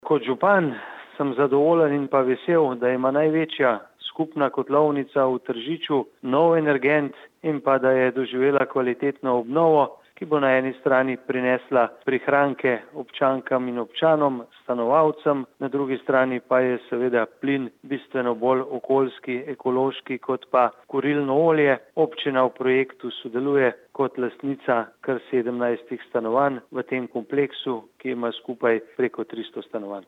izjava_mag.borutsajoviczupanobcinetrzic_ogrevalnisistemdeteljica.mp3 (728kB)